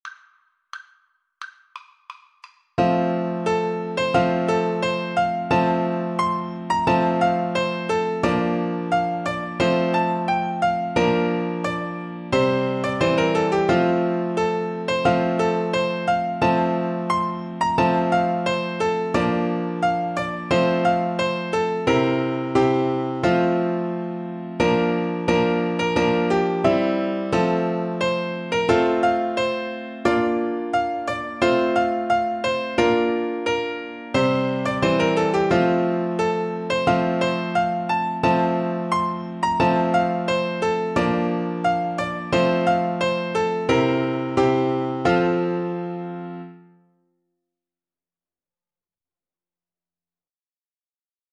Play (or use space bar on your keyboard) Pause Music Playalong - Piano Accompaniment Playalong Band Accompaniment not yet available reset tempo print settings full screen
4/4 (View more 4/4 Music)
F major (Sounding Pitch) C major (French Horn in F) (View more F major Music for French Horn )
Traditional (View more Traditional French Horn Music)